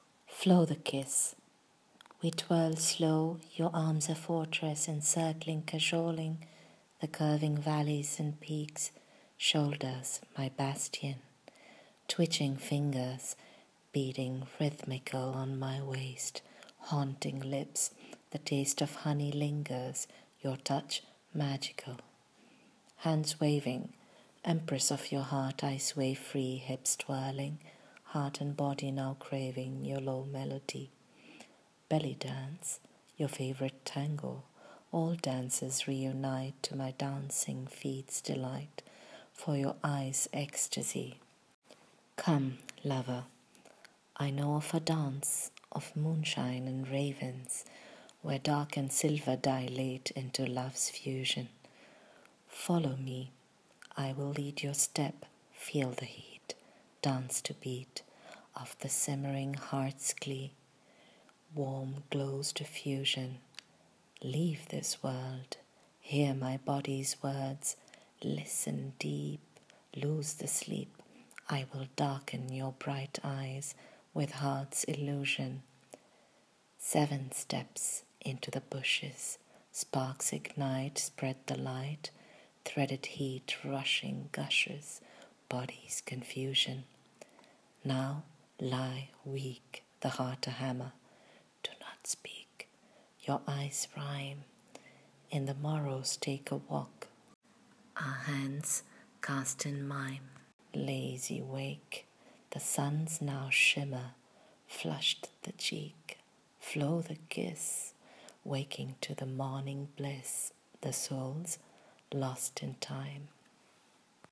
Reading of the poem: